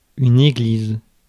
Ääntäminen
Synonyymit mosquée temple synagogue basilique Ääntäminen France: IPA: [e.ɡliz] Haettu sana löytyi näillä lähdekielillä: ranska Käännös Konteksti Substantiivit 1. kirik uskonto, kristinusko Suku: f .